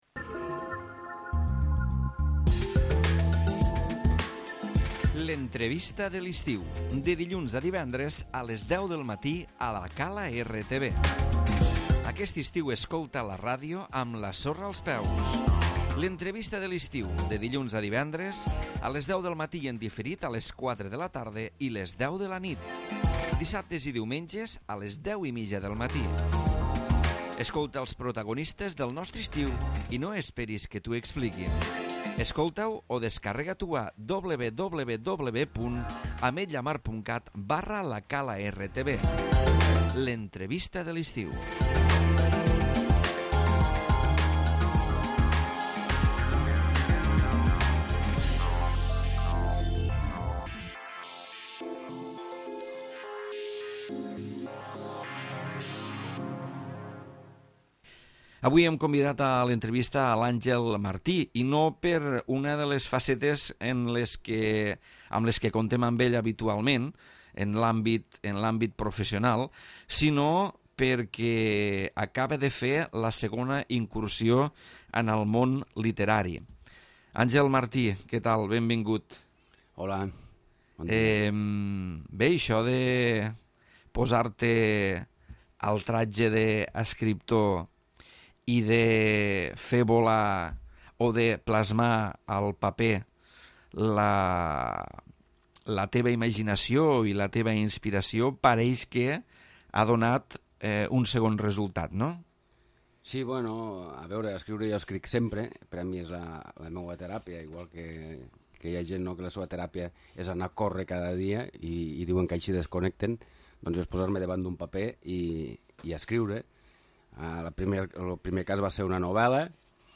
L'Entrevista de l'estiu